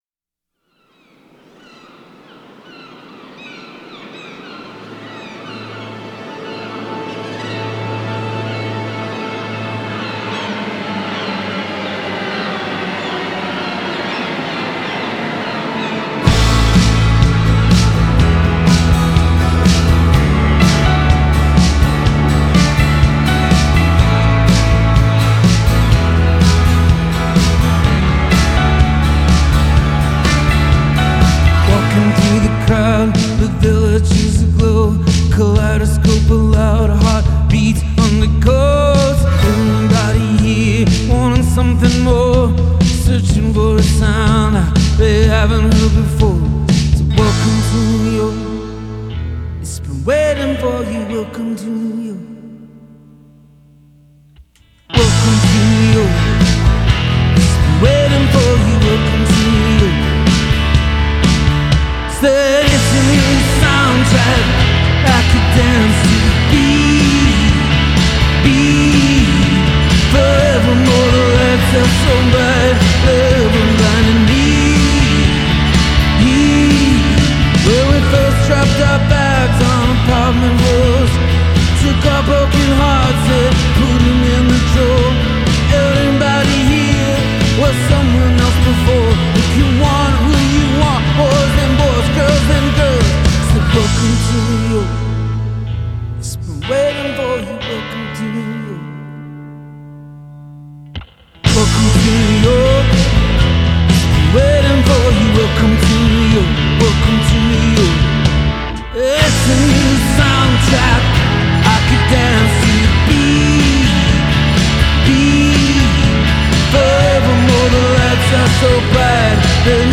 Genre: Rock, Folk, Pop, Covers